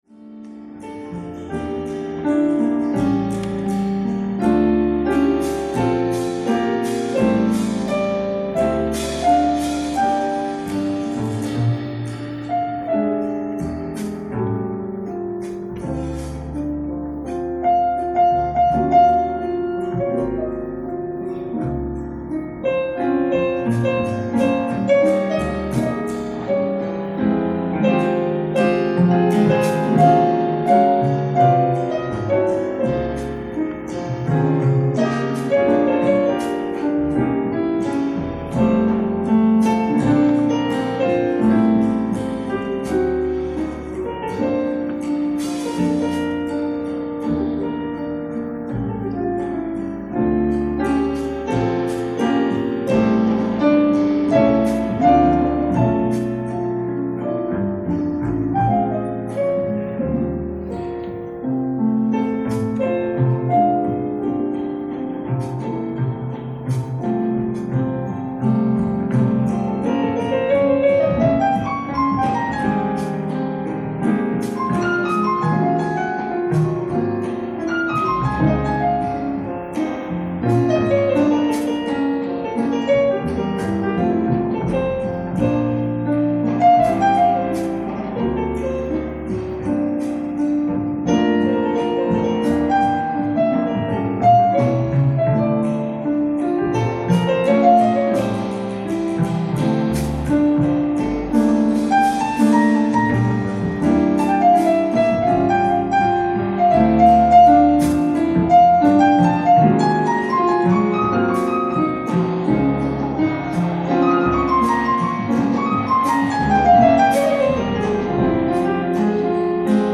ライブ・アット・セジョン・センター、ソウル 05/19/2013
※試聴用に実際より音質を落としています。